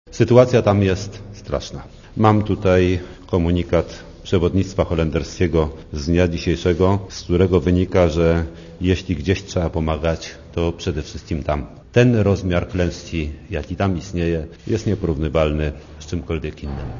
* Mówi wiceminister Truszczyński*
trzesienie-pomoc_na_sumatre.mp3